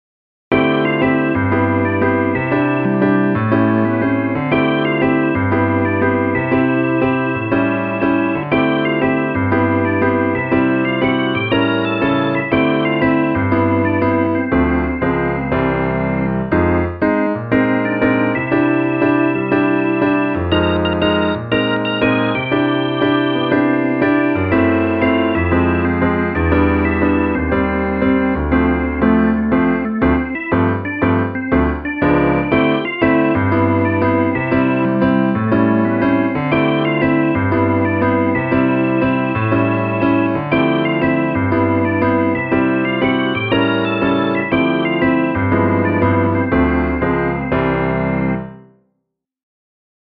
3連符だらけのメロディライン(楽譜)
そのメロディと伴奏つき(MP3)
メロディラインはオルガン。